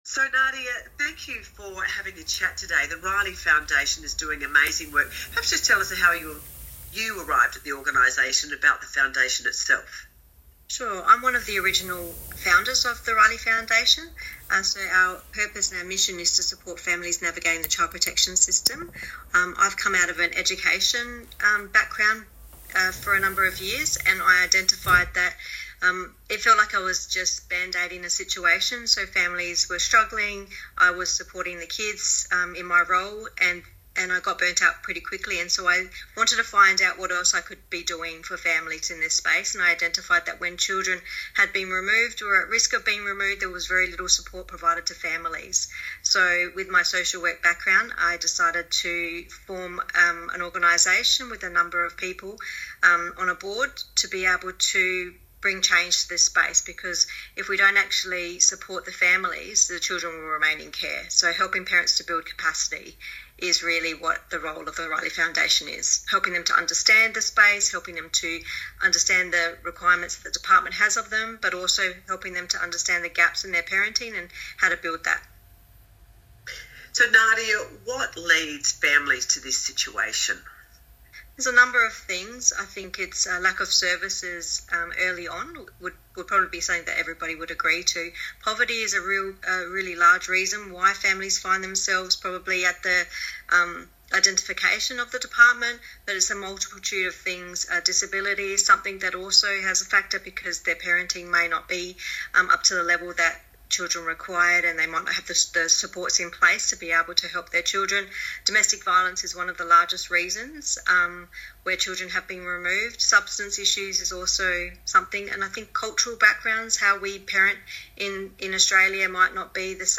FIVEAA-Interview-Feb-2024.m4a